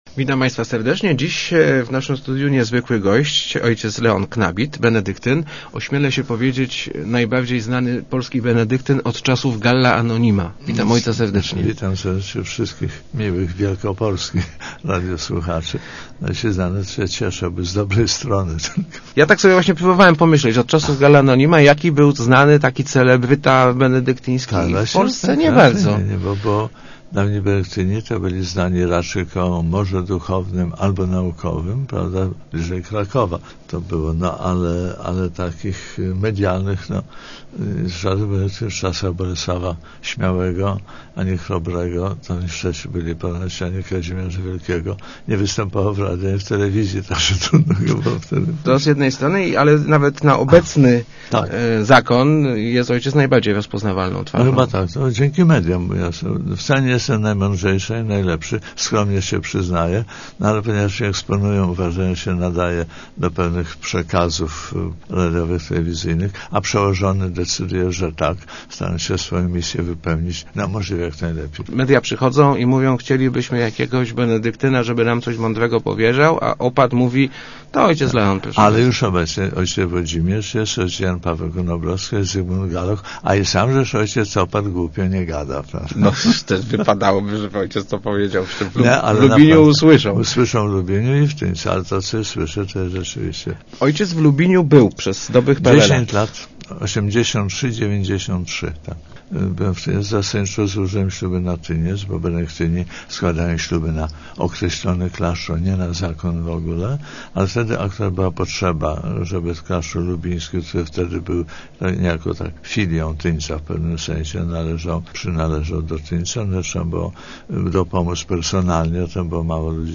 Musimy szukać sposobów na przetrwanie – tłumaczył w Rozmowach Elki najbardziej znany w Polsce mnich o. Leon Knabit. Odwiedził on Leszno w podróży promującej zakon i zakonne wyroby.